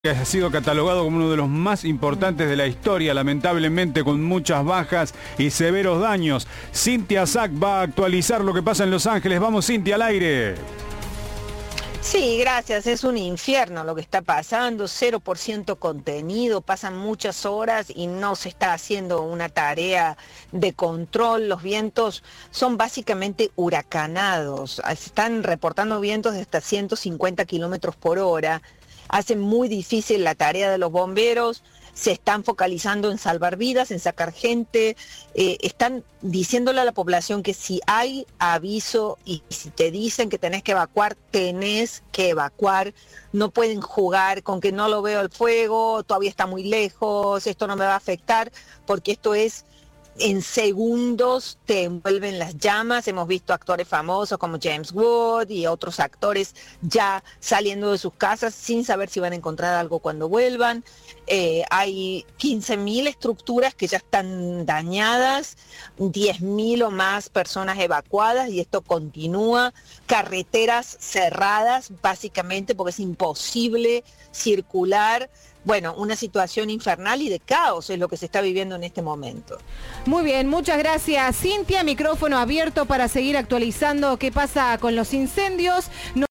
En una entrevista exclusiva con Cadena 3 el gobernador de Córdoba sostuvo que mientras Cristina y Macri sean los dos polos de la grieta va a ser difícil hablar en serio.